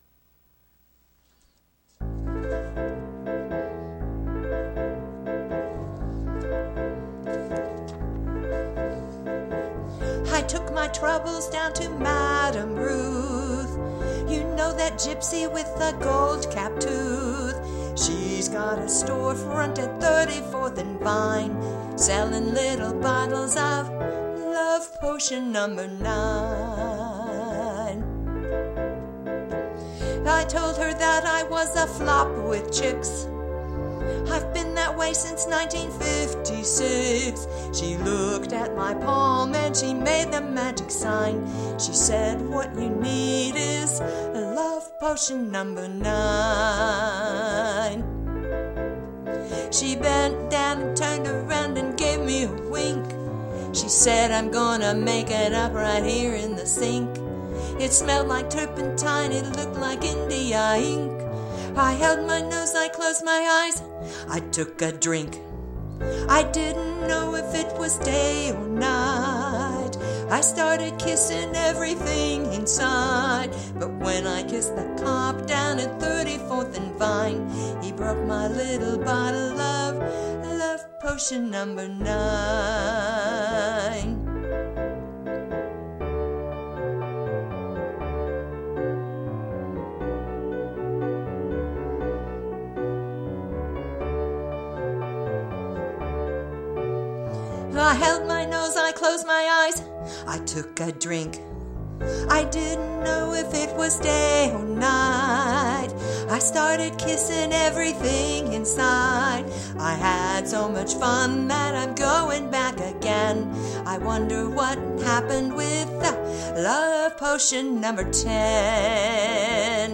VA = Virtual Accompaniment
NOTE: EVERYONE SINGS UNISON, and hams it up a little;